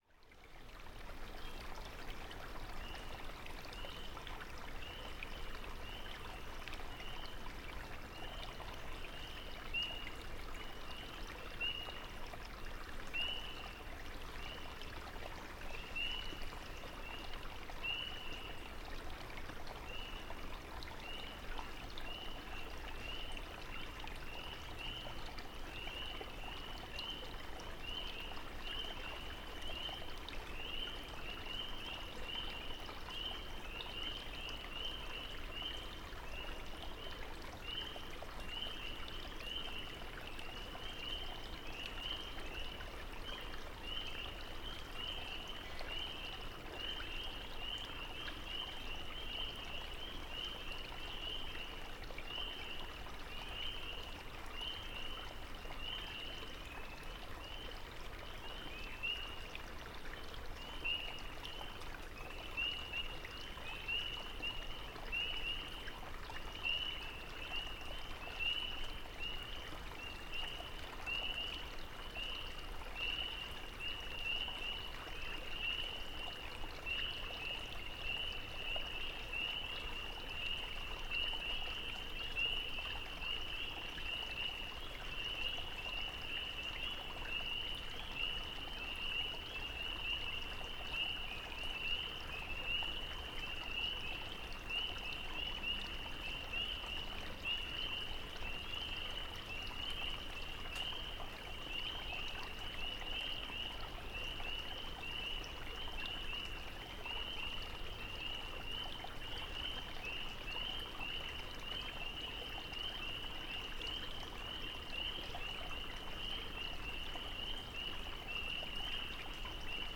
Spring Peepers calling gently at Ladyslipper Pond. 4:45am, 24 May 2016. Connecticut Hill Wildlife Management Area near Ithaca, New York.
At first light, I placed one of my mic setups near the outlet to Ladyslipper Pond, at a position where the gurgle of water can be heard, though not too loudly.
Spring Peepers sound off from the edge of the pond and at several points the soft nasal calls of a drake Mallard can be heard. I find this recording meditative in effect.
It is good-sounding water, with a little on the left and on the right.
gentle+peepers.mp3